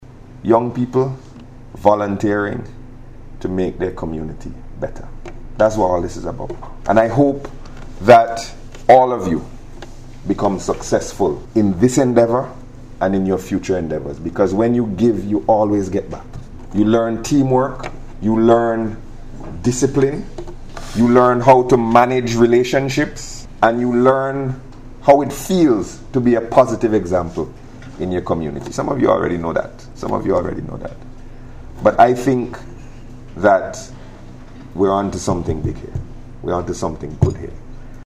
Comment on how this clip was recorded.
He made the statement while addressing the official handover ceremony of equipment to the first five community-based youth groups under the national volunteer support programme.